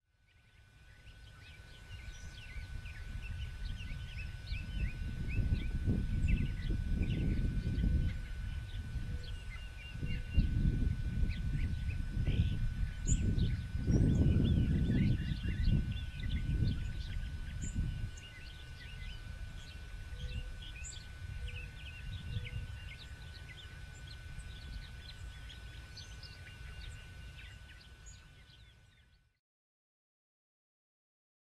Common Ground-Dove  MOV  MP4  M4ViPOD  WMV